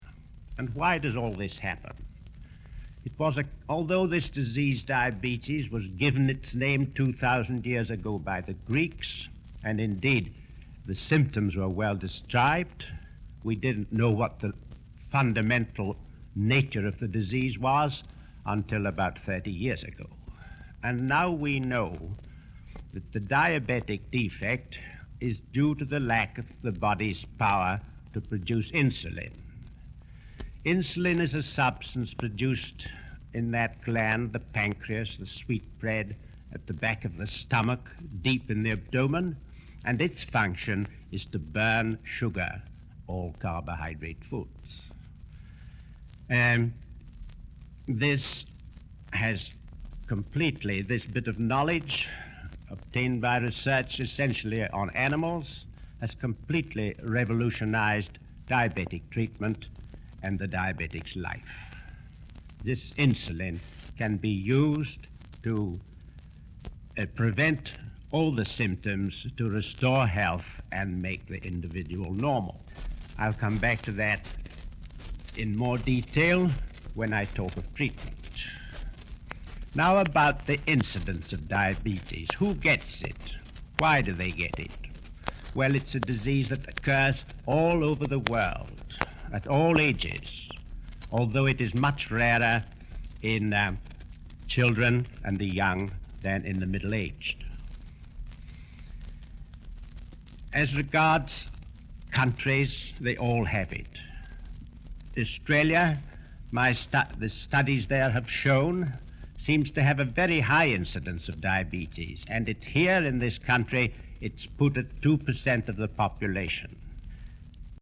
This is a recording of a speech made in 1953.